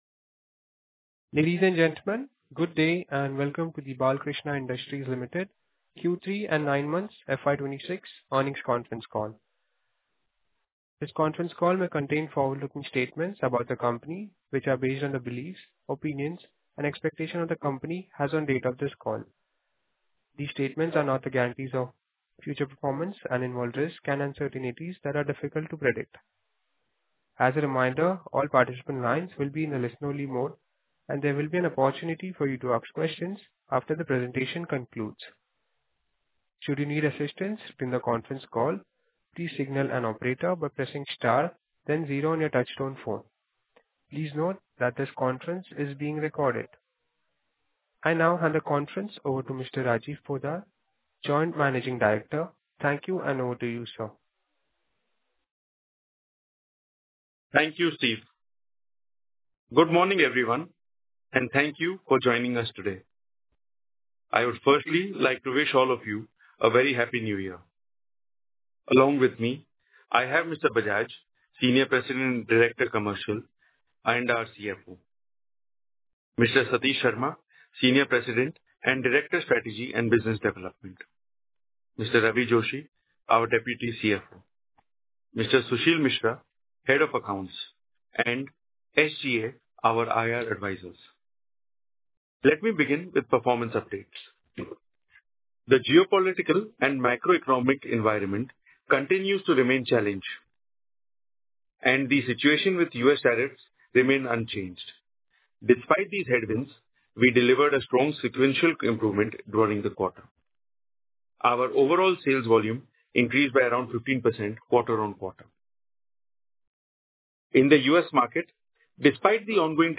Audio recordings of conference Call dated 29.01.2026